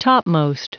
Prononciation du mot topmost en anglais (fichier audio)
Prononciation du mot : topmost